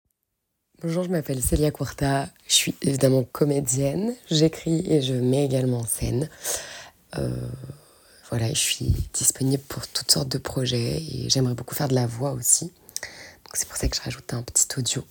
juste ma voix
Voix off